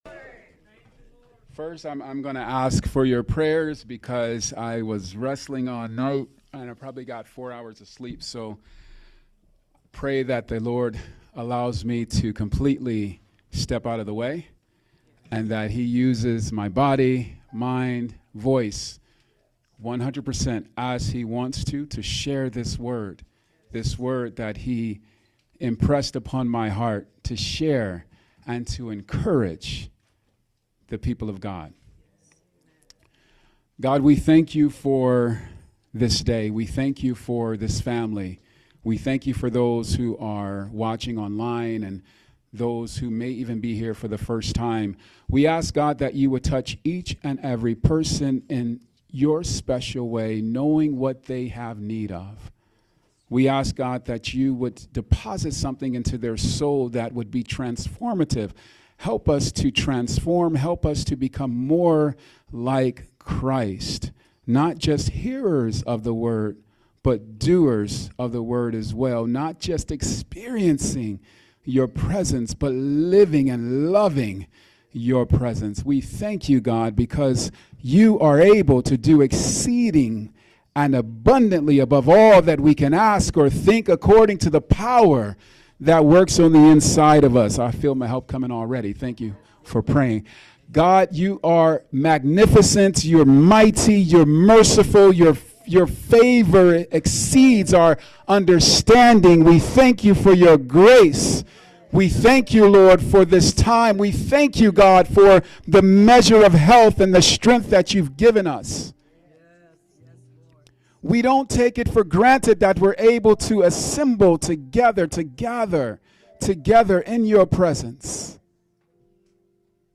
The goal of the sermon is to provide believers with “personal ammunition”—spiritual truths they can use to rebut the negative labels or internal thoughts that contradict their true identity in Christ.